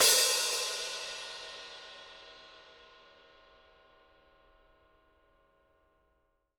R_B Hi-Hat 09 - Close.wav